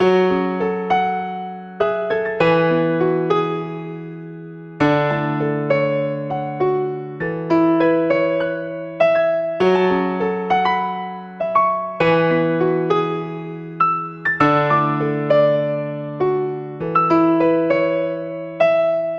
描述：F中的钢琴循环 MINOR; 你可以在我的Looperman个人资料页面上找到其他很酷的东西希望你能找到有用的东西。
Tag: 100 bpm RnB Loops Piano Loops 3.23 MB wav Key : Unknown